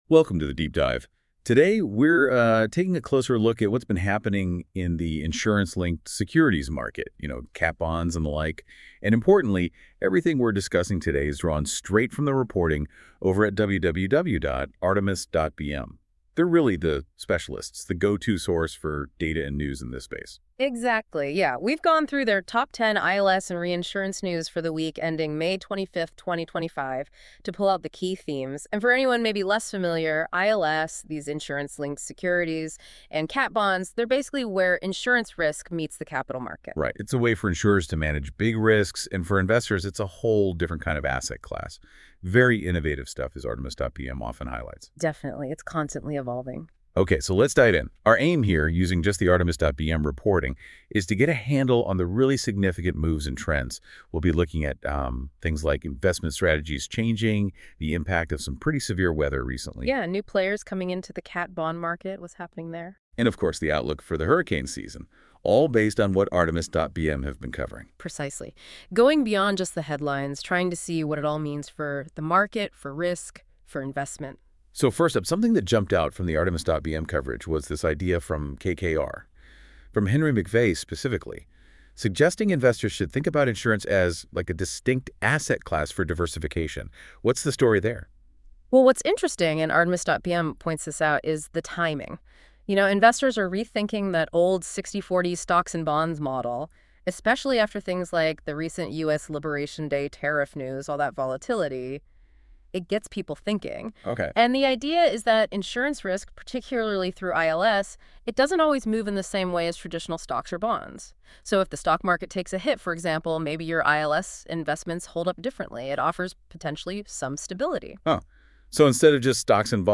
As we continue to experiment with artificial intelligence tools, we hope this summary provides an easy way to listen to a recap of some of last week's top stories. Featuring news about the catastrophe bond market and record issuance levels tracked by Artemis, new ILS focused launches, and much more.